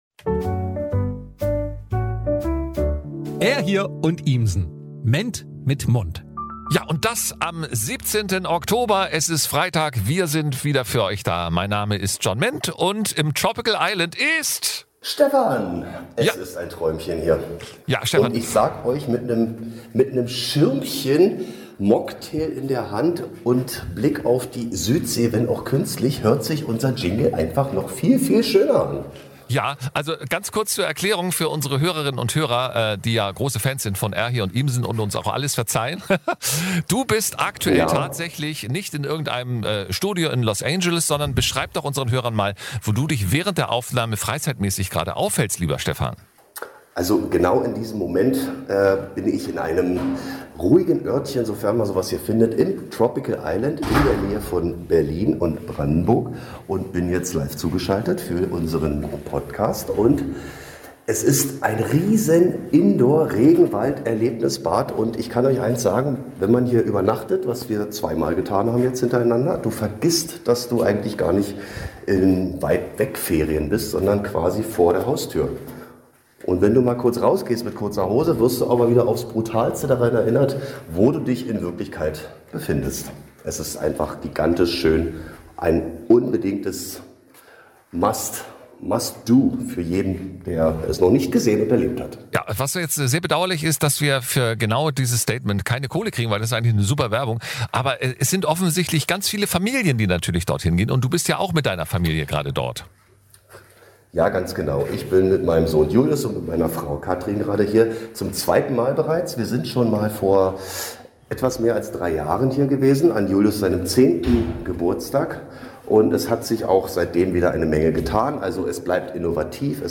Folge 25 - Pool-Position! Live aus dem Schwimmbad!